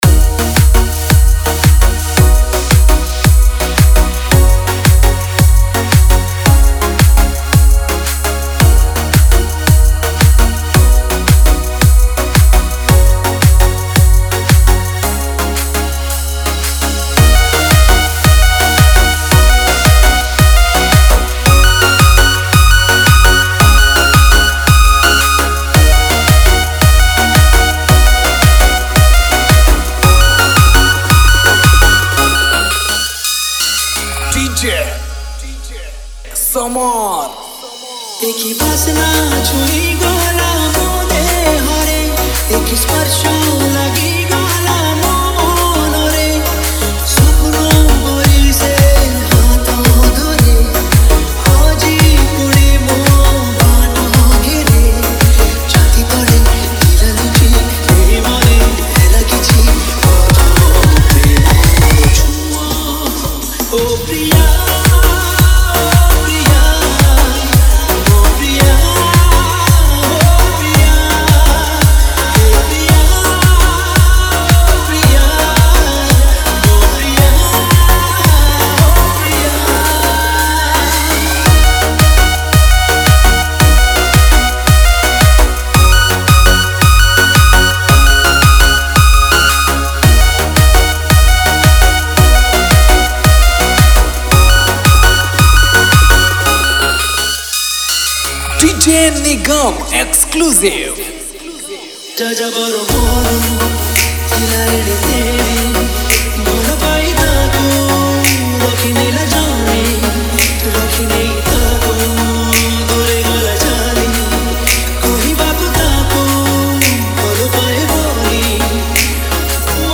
Romantic Love Dj Remix